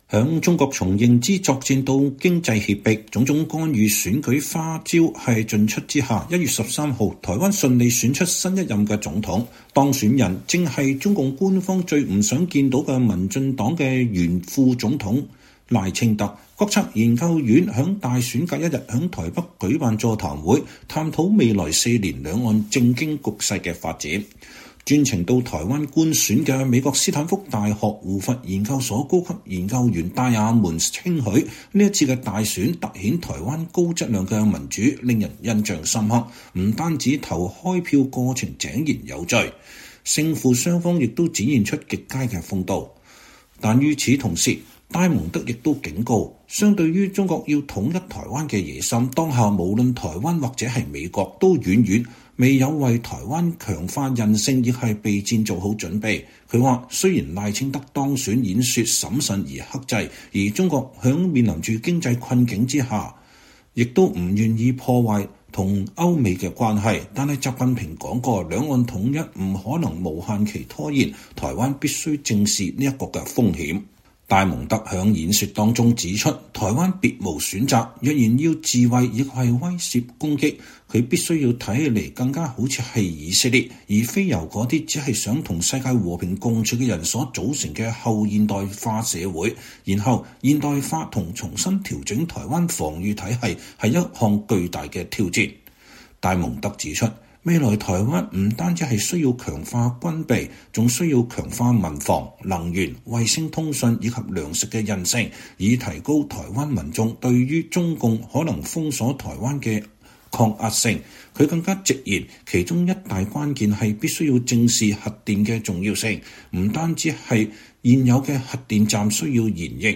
「國策研究院」於大選隔天在台北舉辦座談會，探討未來四年兩岸政經局勢發展。